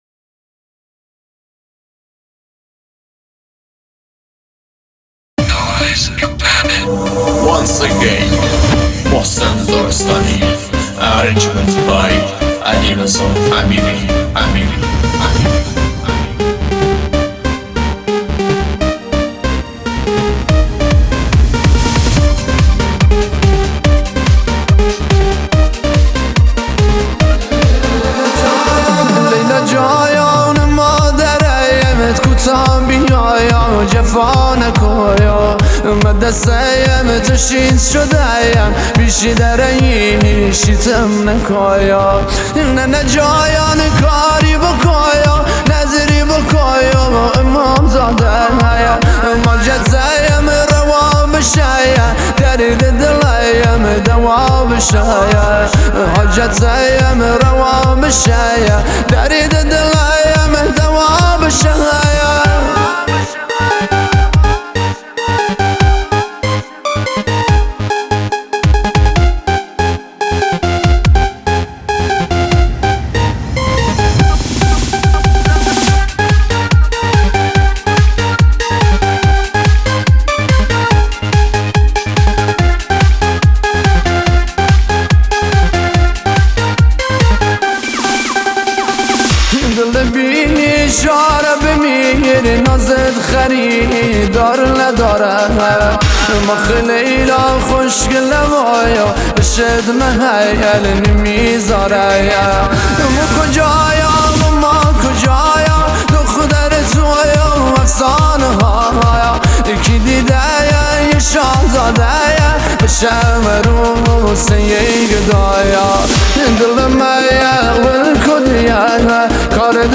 ترانه نوستالژیک و پرطرفدار